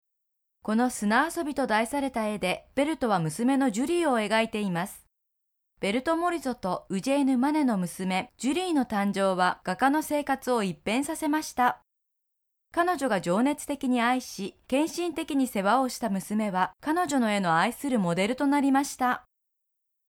Clear, warm, sincere and friendly Japanese voice with 16 years experiences!
Sprechprobe: eLearning (Muttersprache):